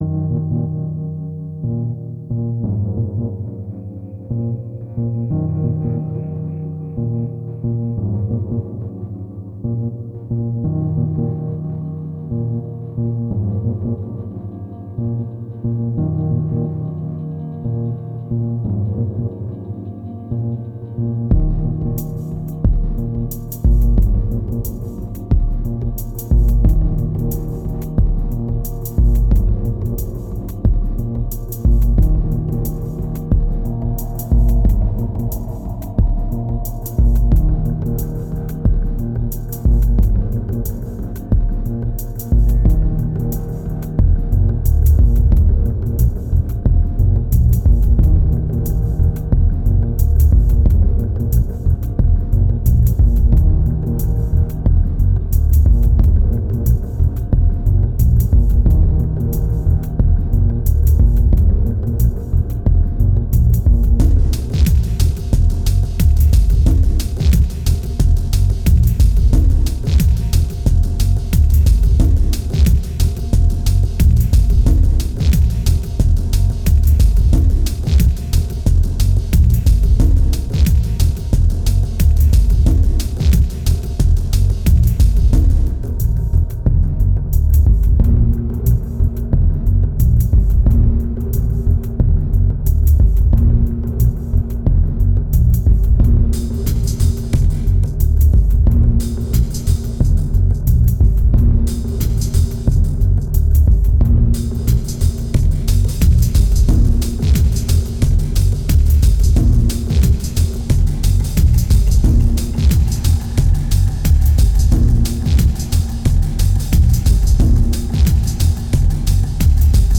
2207📈 - 32%🤔 - 90BPM🔊 - 2010-12-04📅 - -92🌟